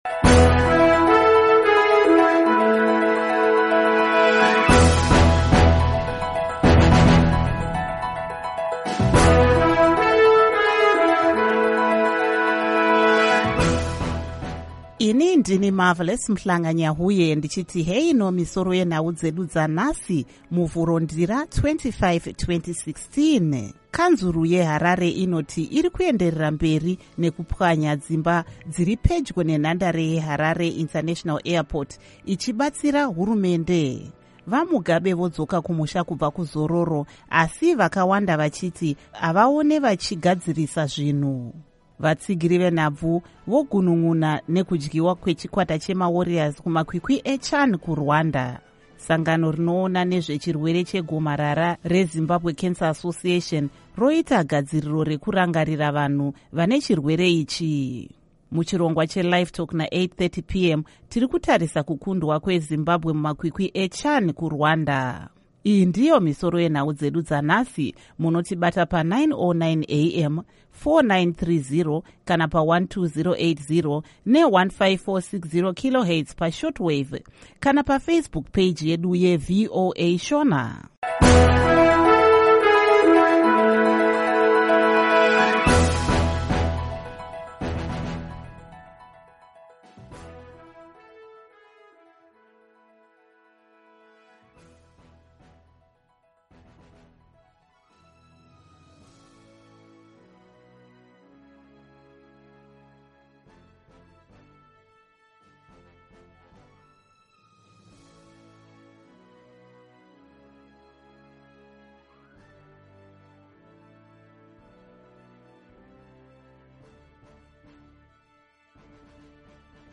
Misoro Yenhau Dzanhasi Muvhuro, Ndira, 25, 2016